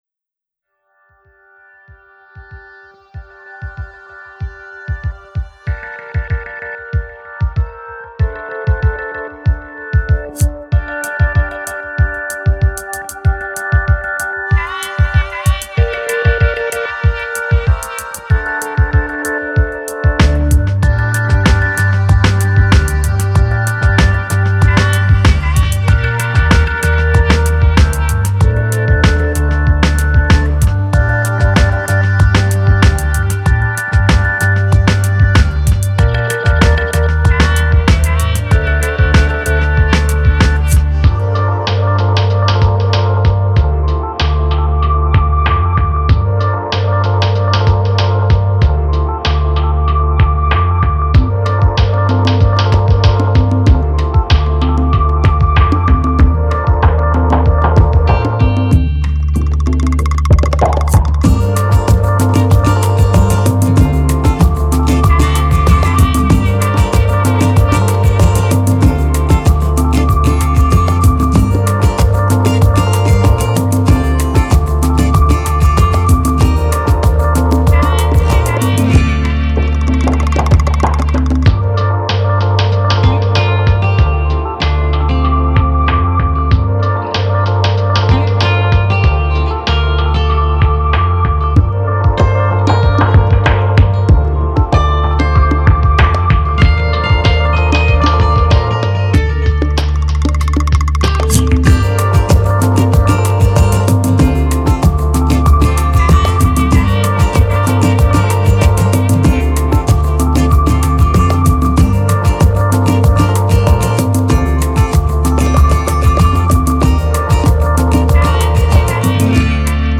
Brazilian Bossa Vibe